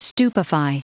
Prononciation du mot stupefy en anglais (fichier audio)
Prononciation du mot : stupefy